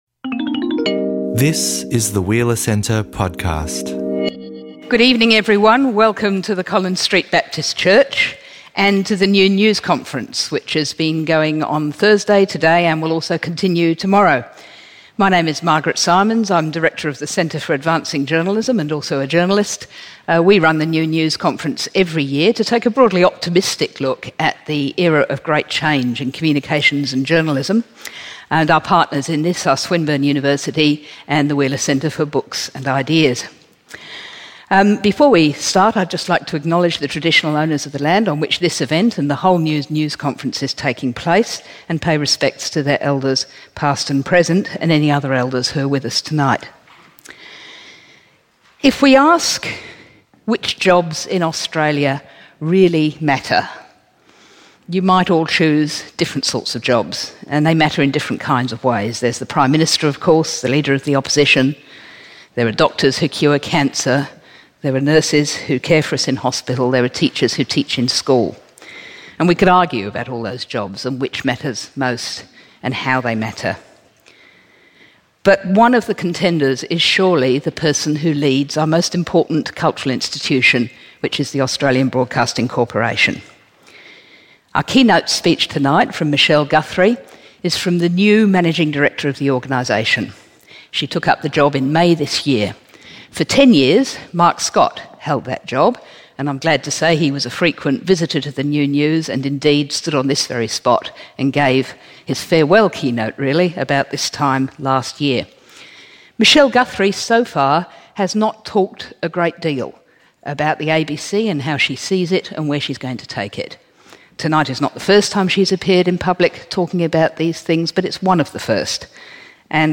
Presenting a keynote address at New News 2016, hear from new ABC Managing Director Michelle Guthrie about digital disruption and the role of the ABC in Australian democracy. What vision does she put forward for the national broadcaster?
Her keynote address is followed by an animated Q&A, hosted by journalist and Centre for Advancing Journalism Director Margaret Simons.